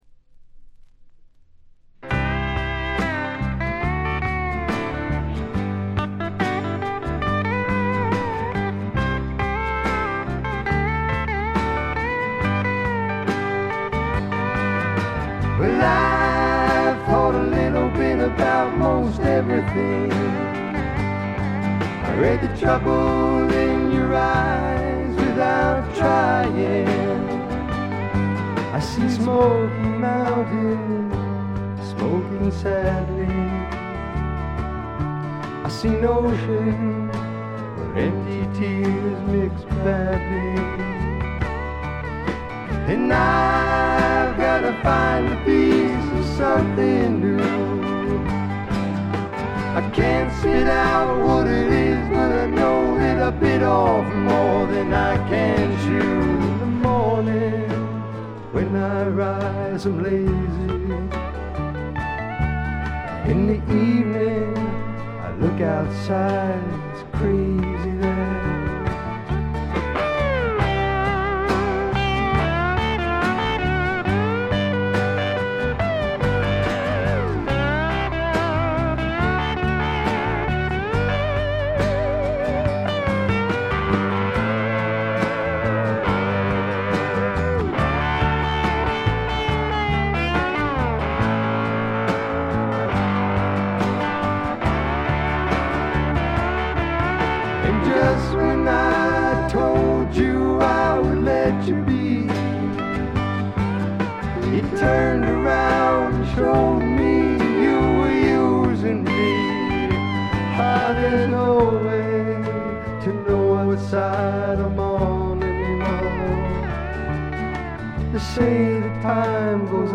微細なチリプチがほんの少し。
試聴曲は現品からの取り込み音源です。
こちらもご覧ください　 レコード：米国 SSW / フォーク